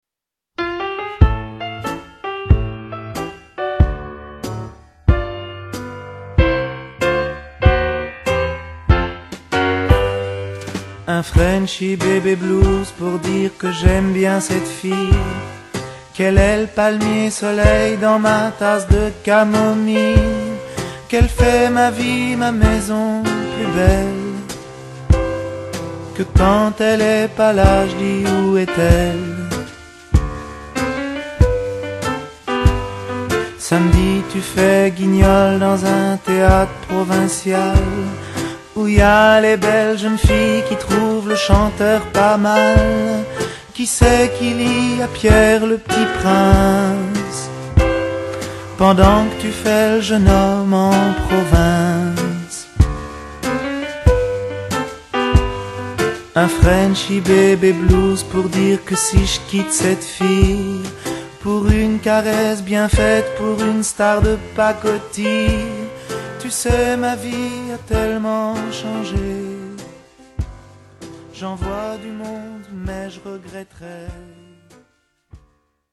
tonalité DO majeur